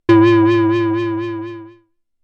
不思議系効果音です。
ぽわぽわぽわわーん・・・